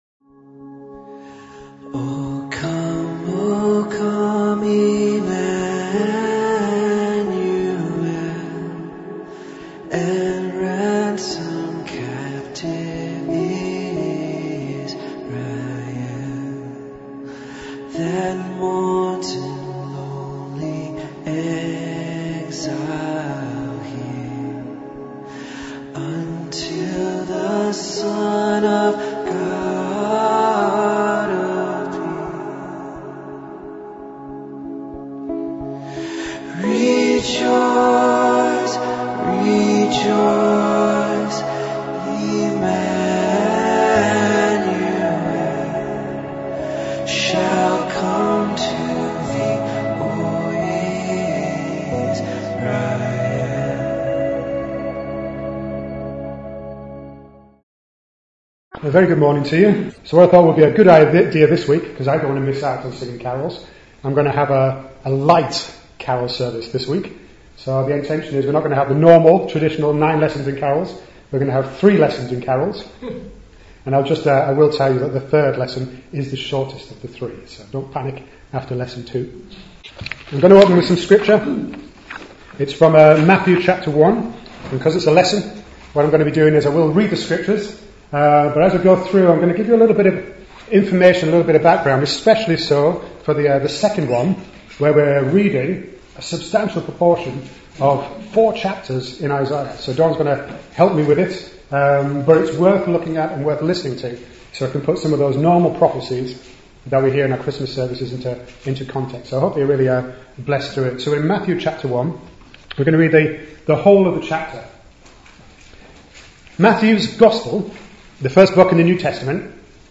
Matthew 1 and Isaiah 7-11 - Message.mp3